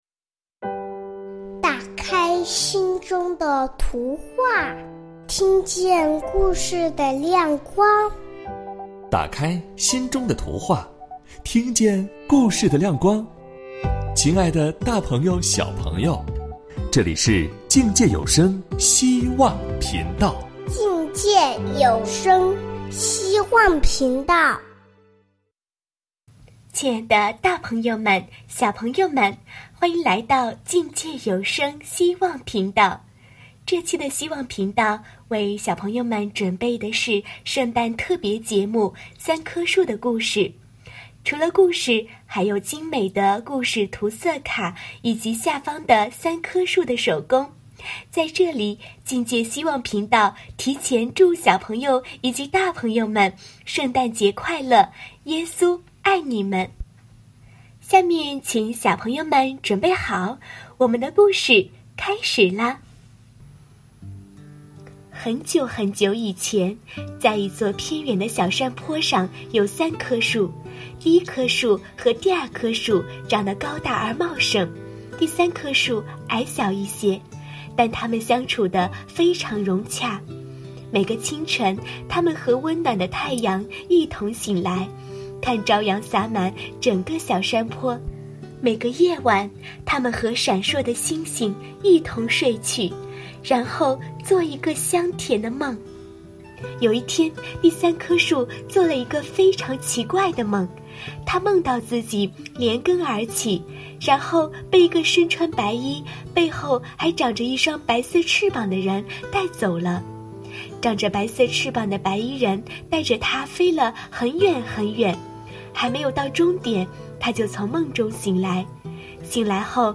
播音